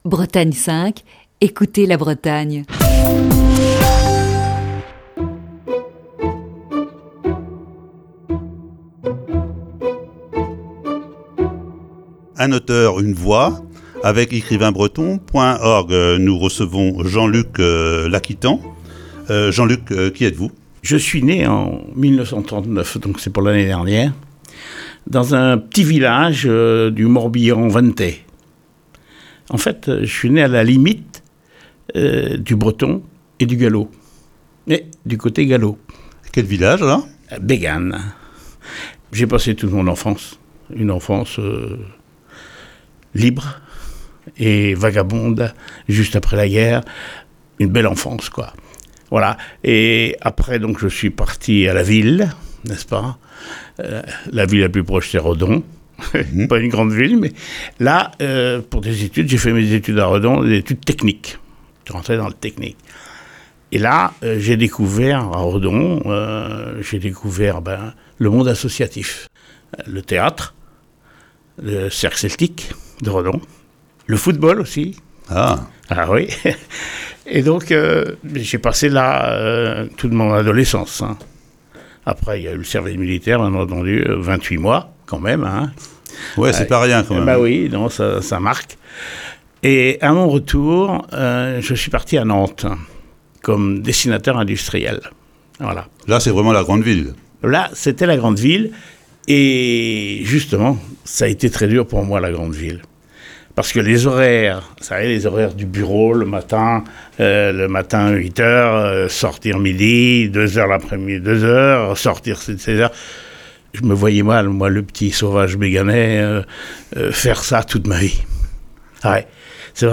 Ce lundi première partie de cette série d'entretiens.